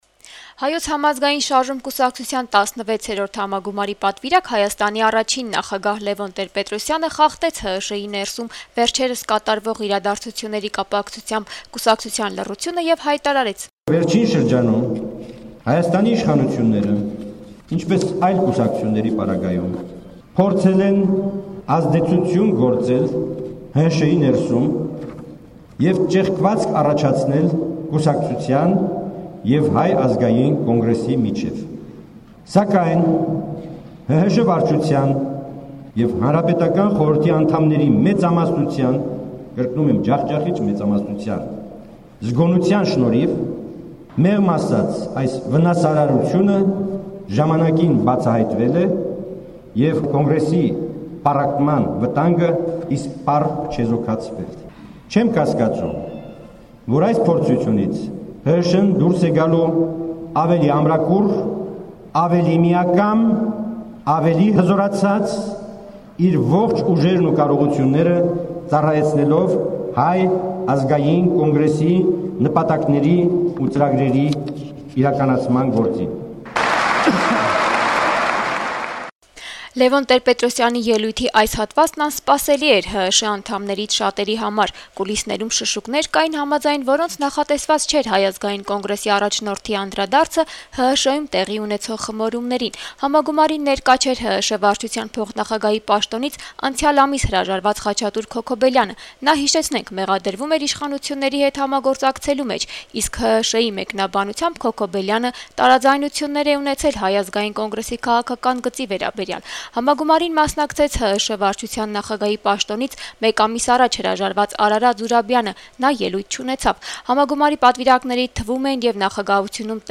Շաբաթ օրը Կառավարության նիստերի դահլիճում մեկնարկեց Հայոց համազգային շարժման հերթական համագումարը: Հայ ազգային կոնգրեսի առաջնորդ, Հայաստանի առաջին նախագահ Լեւոն Տեր-Պետրոսյանը, ով համագումարի պատվիրակների թվում է, իր ելույթում ներկաներից շատերի համար անսպասելիորեն անդրադարձավ վերջին շրջանում կուսակցության ներսում տեղի ունեցող խմորումներին: